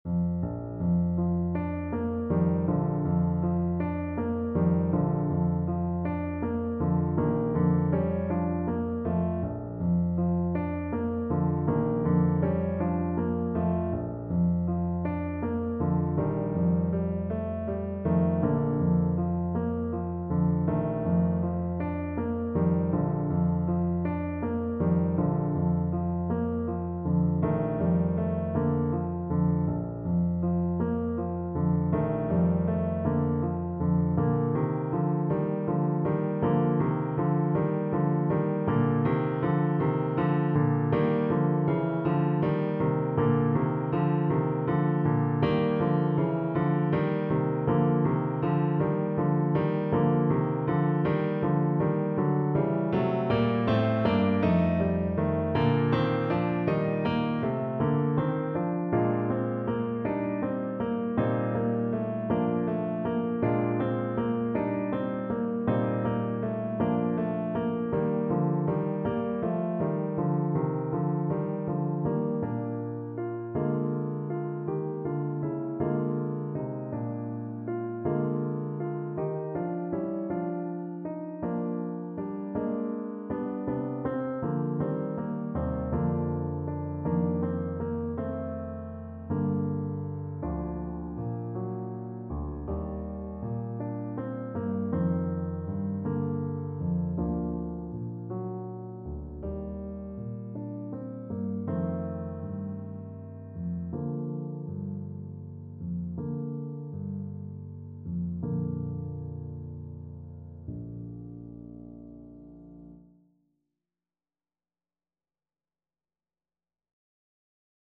Traditional Charles Stanford Ancient Lullaby (Irish Folk Song) Clarinet version
Play (or use space bar on your keyboard) Pause Music Playalong - Piano Accompaniment Playalong Band Accompaniment not yet available transpose reset tempo print settings full screen
Clarinet
6/8 (View more 6/8 Music)
Bb major (Sounding Pitch) C major (Clarinet in Bb) (View more Bb major Music for Clarinet )
Andante
Traditional (View more Traditional Clarinet Music)